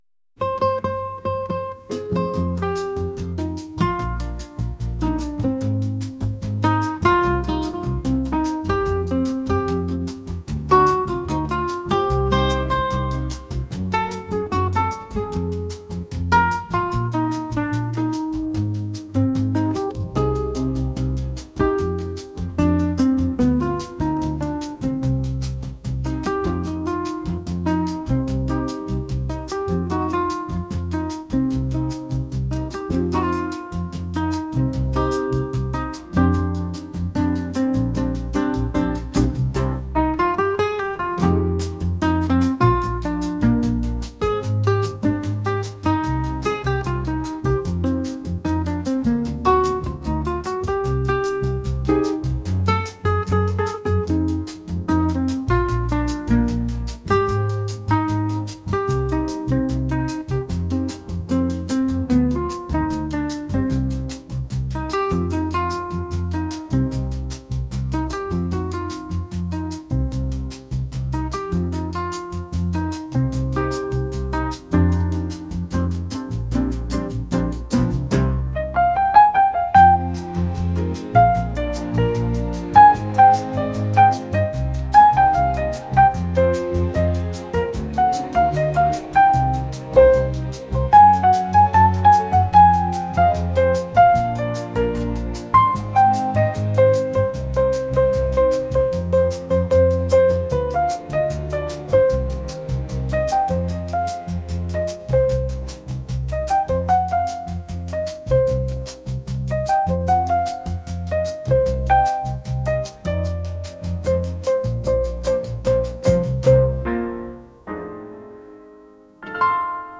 latin | smooth